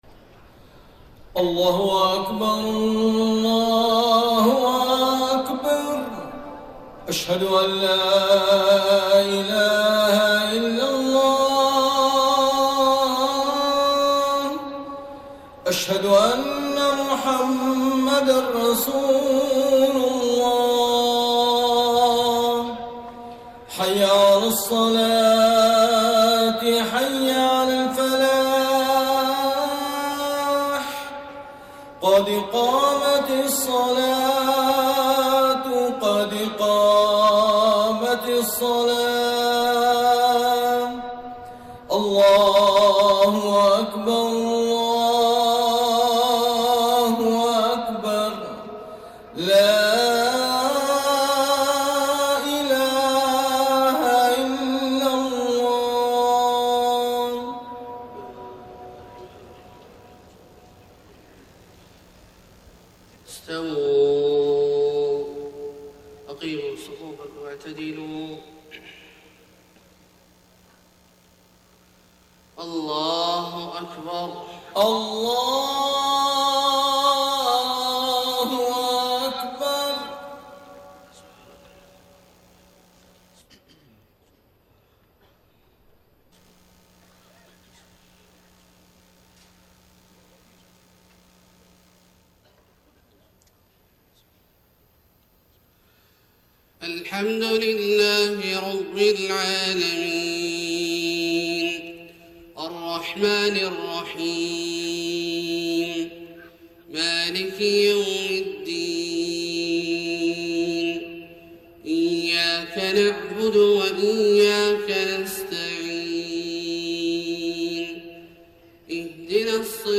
صلاة الفجر 15 ربيع الأول 1431هـ من سورة البقرة {83-91} > 1431 🕋 > الفروض - تلاوات الحرمين